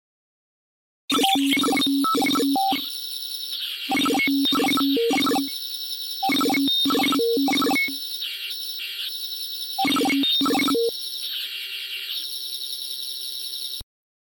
Tiếng máy tính Đọc Dữ Liệu điện tử phim khoa học viễn tưởng
Hiệu ứng Máy móc vang vọng Viễn tưởng Tiếng chuyển đổi Dữ liệu lớn Khoa học viễn tưởng
Thể loại: Hiệu ứng âm thanh
Description: Tiếng máy tính Đọc Dữ Liệu điện tử viễn tưởng là âm thanh của máy móc xử lý dữ liệu, âm thanh đặc trưng của khoa hoạc, âm thanh của phim viễn tưởng gợi ra sự sáng tạo vô biên về trí não con người, âm thanh máy móc xử lý dữ liệu khổng lồ tạo cho người xem sự hứng thú đối với bộ phim.
Tieng-may-tinh-doc-du-lieu-dien-tu-phim-khoa-hoc-vien-tuong-www_tiengdong_com.mp3